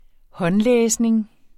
Udtale [ -ˌlεːsneŋ ]